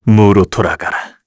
Lusikiel-Vox_Skill1_kr.wav